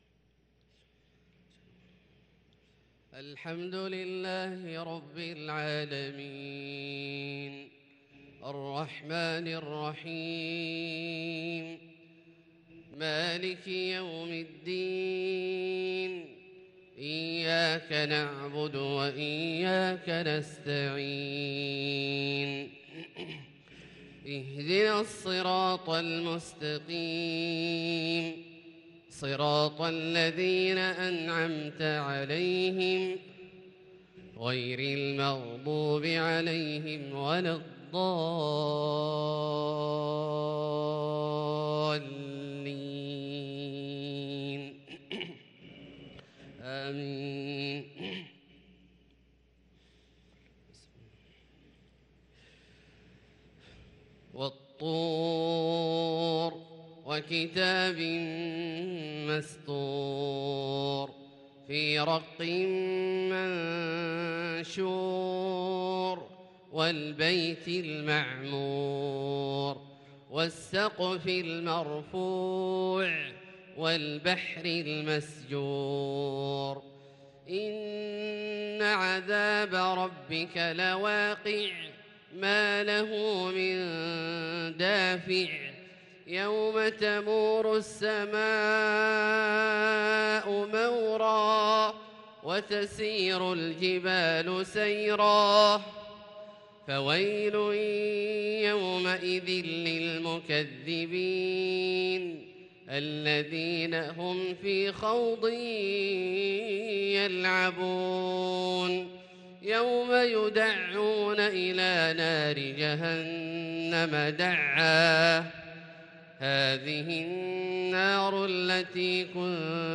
صلاة الفجر للقارئ عبدالله الجهني 8 ذو القعدة 1443 هـ